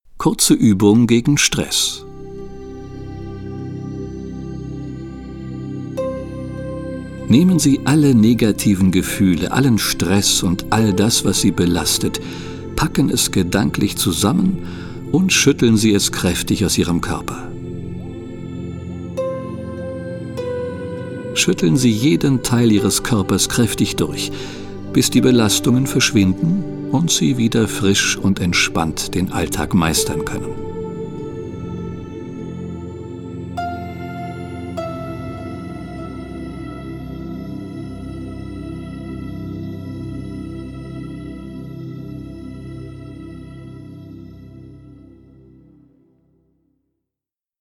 Die ruhige und einfühlsame Stimme des Sprechers
führt sie durch die einzelnen Übungen, die mit spezieller Entspannungsmusik unterlegt sind.